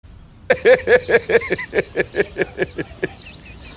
Смех главного героя в сериале